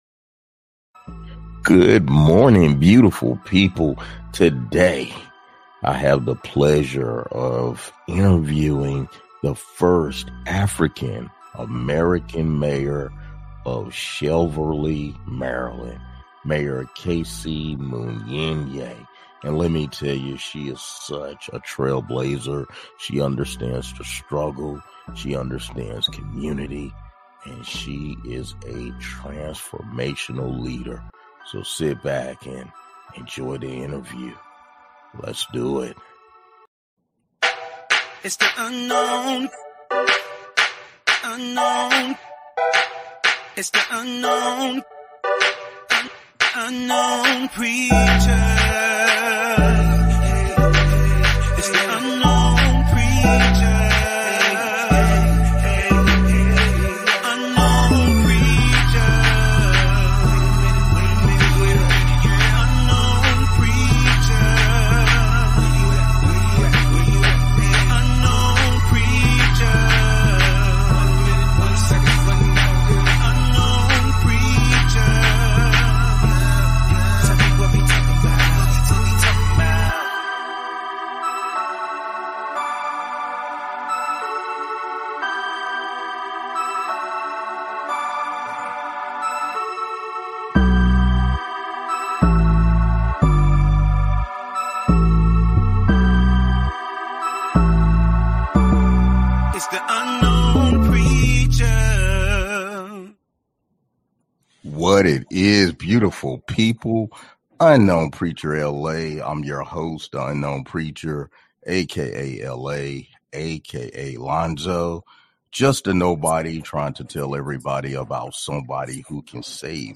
speaks with the Mayor of Cheverly, Maryland, Kayce Munyeneh.